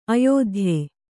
♪ ayōdhye